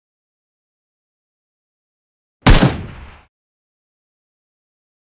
Akm Sound effect Single Shot sound effects free download